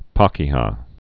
(päkē-hä, -kē-ə)